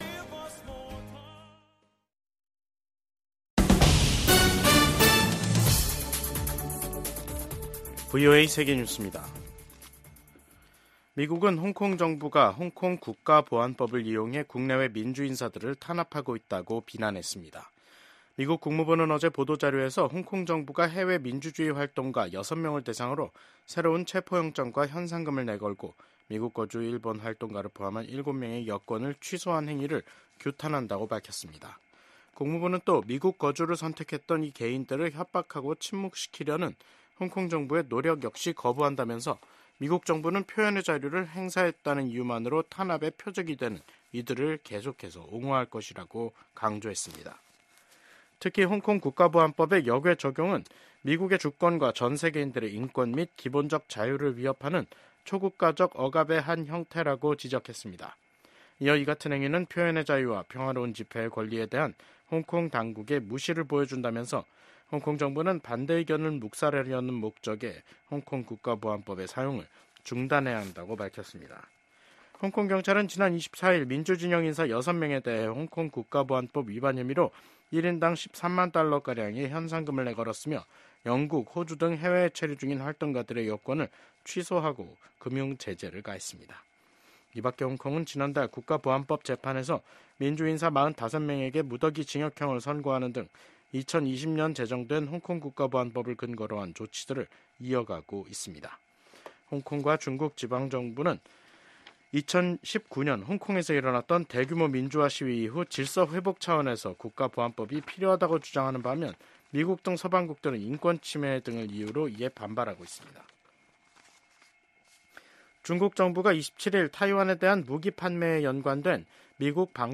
VOA 한국어 간판 뉴스 프로그램 '뉴스 투데이', 2024년 12월 27일 3부 방송입니다. 한덕수 한국 대통령 권한대행 국무총리의 탄핵 소추안이 국회에서 가결됐습니다. 한국 국가정보원은 북한 군 1명이 러시아 쿠르스크 전장에서 생포됐다는 우크라이나 매체 보도에 대해 사실이라고 밝혔습니다. 미국 전문가들은 역내 안보환경을 고려할 때 미한일 협력의 필요성이 여전히 높다고 평가했습니다.